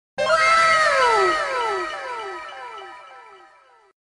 Anime Wow Sound Effect Free Download
Anime Wow